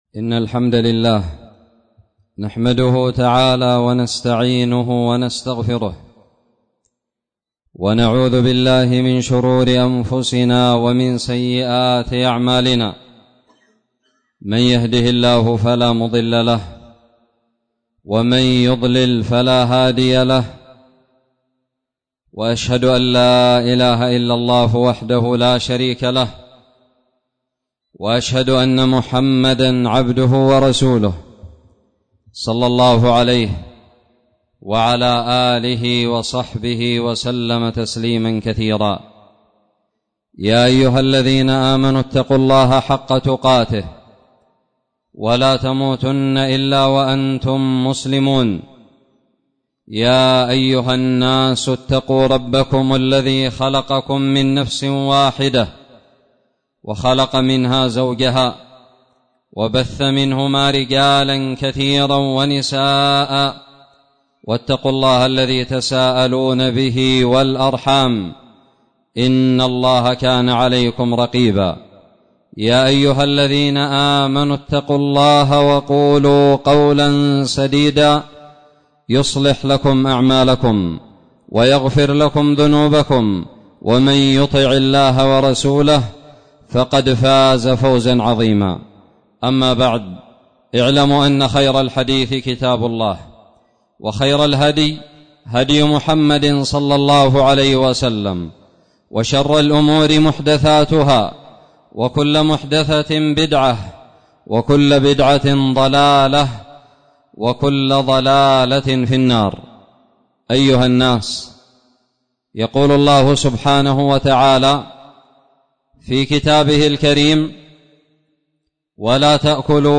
خطب الجمعة
ألقيت بدار الحديث السلفية للعلوم الشرعية بالضالع في 12 ذي القعدة 1441هــ